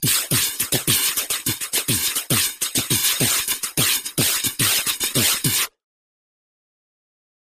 Human Beat Box, Bass Drum And Hi-Hats Beat, Type 1 - Long